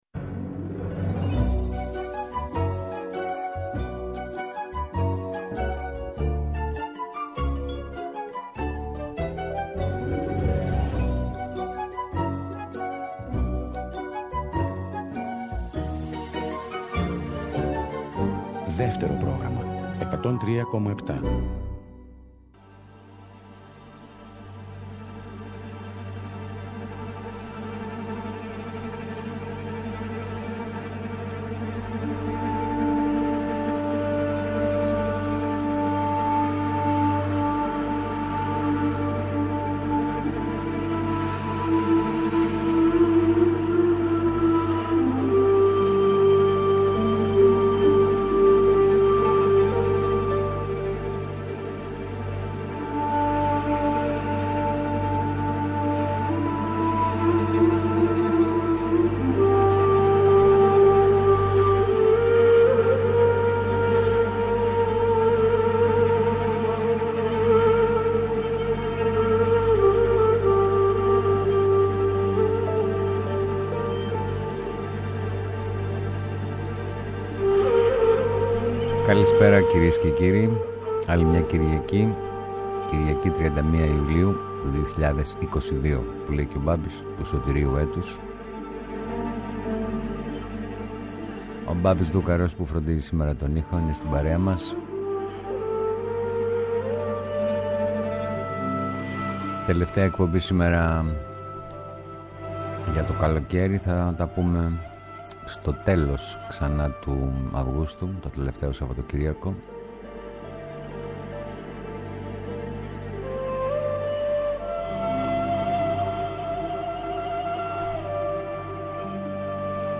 ΔΕΥΤΕΡΟ ΠΡΟΓΡΑΜΜΑ
Εκπομπές Μουσική